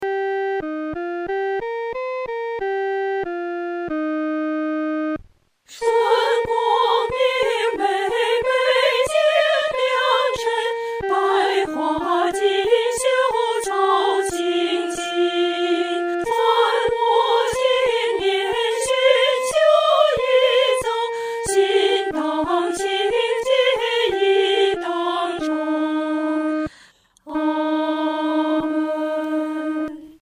女高
本首圣诗由网上圣诗班录制